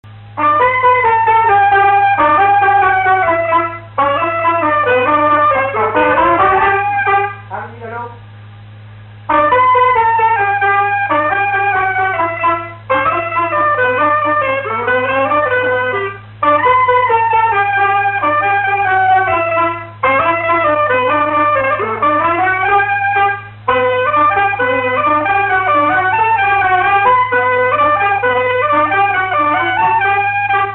clarinette
Vendée
instrumental
Pièce musicale inédite